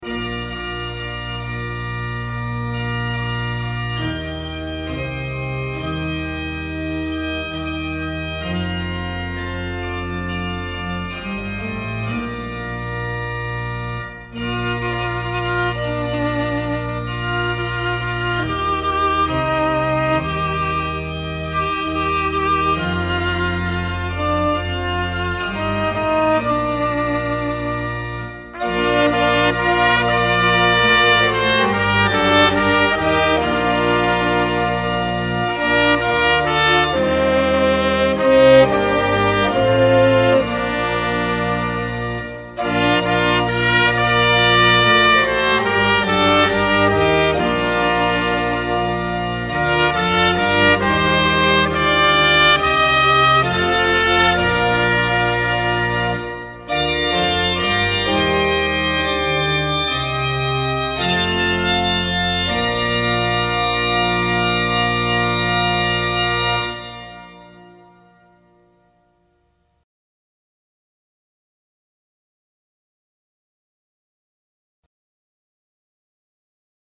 Orgel
Trompete
Querflöte, Sopransax
Violine